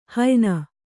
♪ hayna